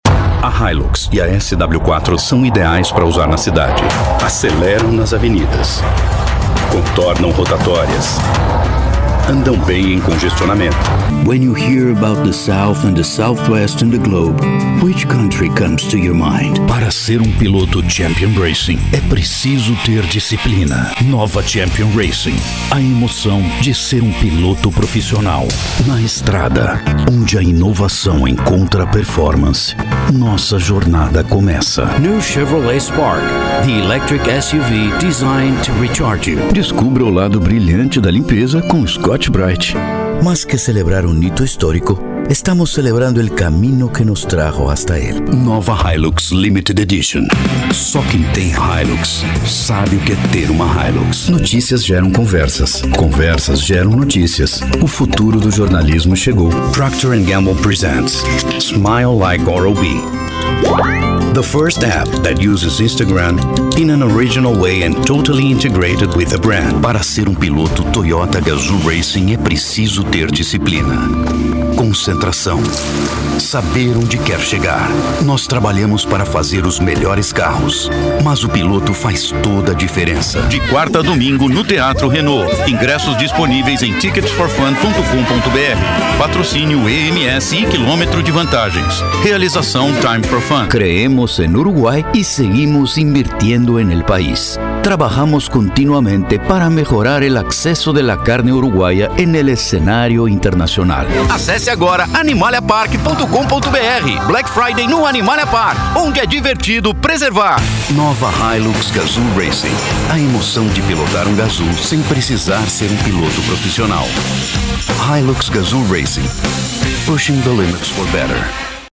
Locutores Profissionais, locução publicitária, locutores comerciais.
Sua base como cantor permite desenvolver trabalhos com interpretações e sotaques diversificados, sempre visando atender às necessidades dos clientes.
Atuação: Locutor(a)
Sotaque: Neutro Masculino